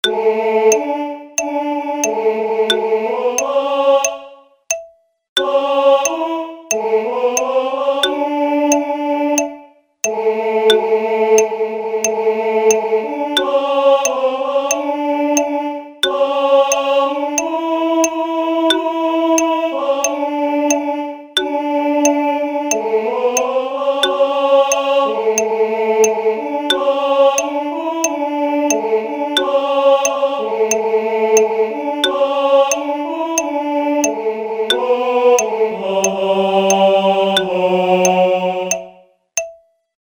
Tenor z metronomom
Z_Bogom_Marija_pravi_TENOR_..mp3